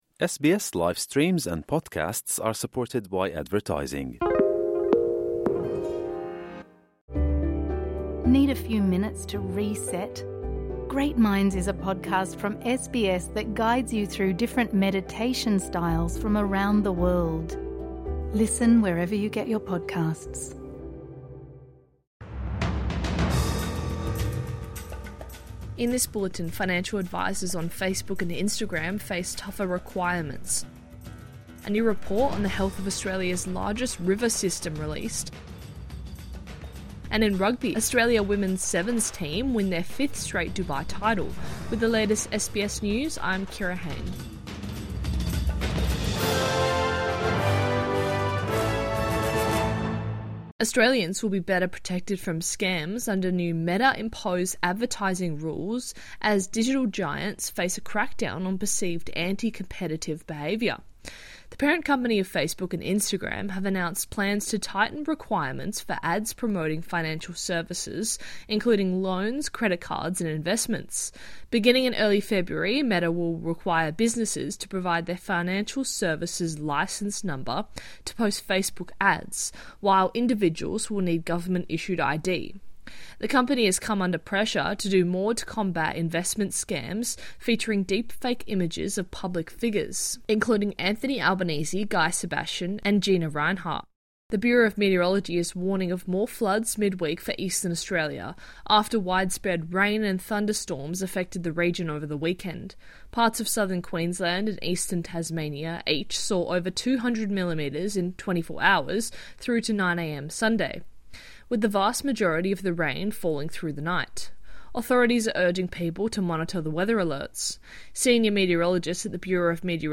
Midday News Bulletin 2 December 2024